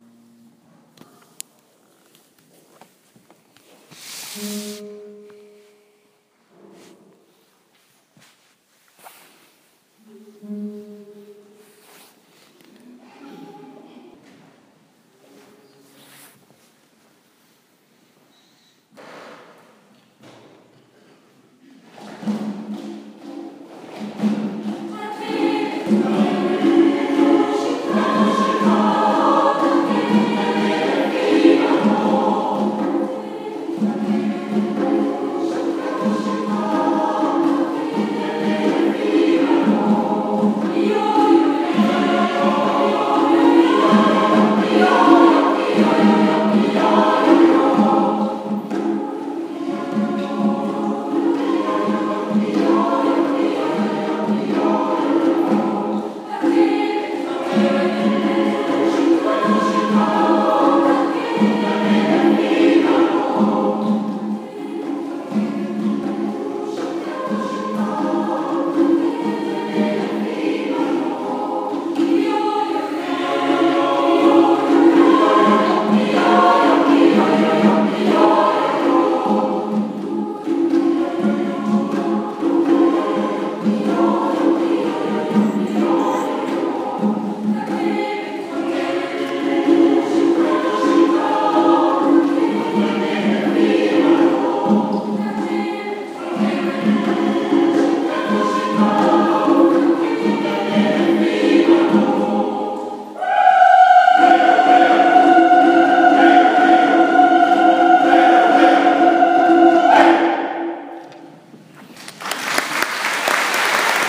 Zahlreiche Frauen und auch einige Männer verschönern mit ihrem Gesang nicht nur den wöchentlichen Sonntagsgottesdienst, sondern auch viele kirchliche Feste und Feiern.